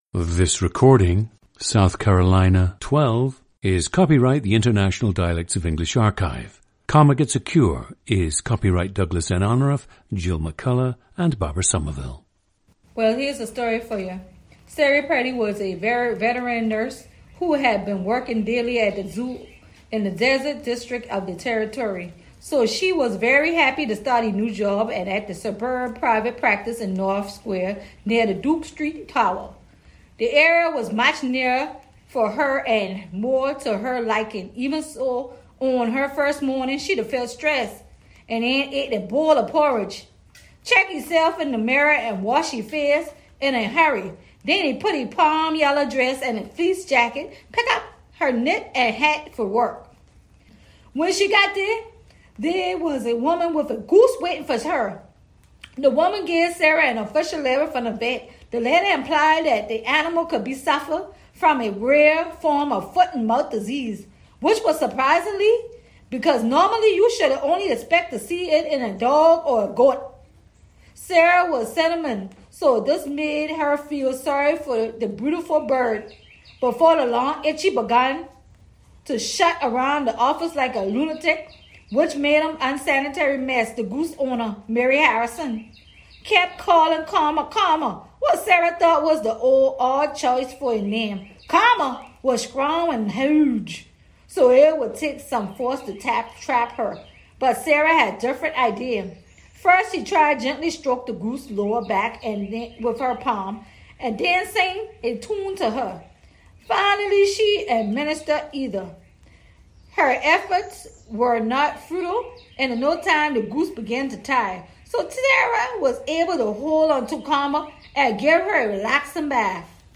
GENDER: female
Speech is mostly non-rhotic. Contraction of “going to” and “go on” are often pronounced “gwan” or “gon.’” Note the pen-pin merger on words like “then,” “them,” and “remember.” ð in initial position intermittently becomes “d” in words like “them” and “that.
Notice how adept she is at codeswitching.
The recordings average four minutes in length and feature both the reading of one of two standard passages, and some unscripted speech.